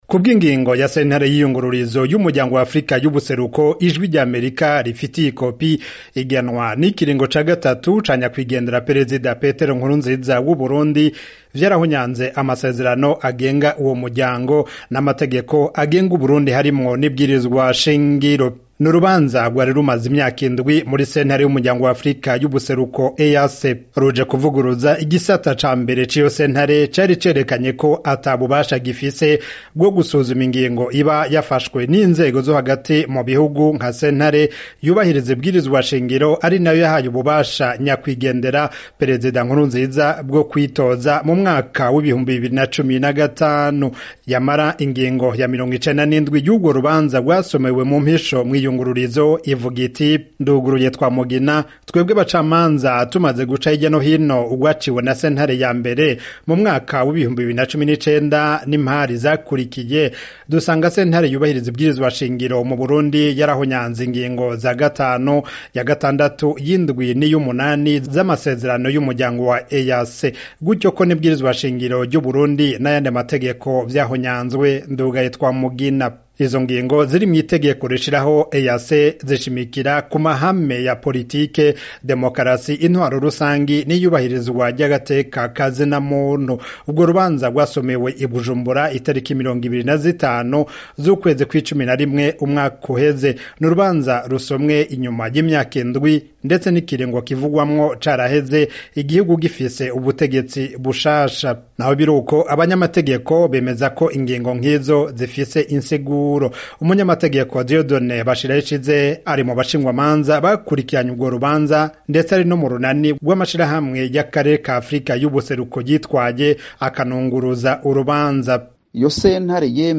Amakuru mu Karere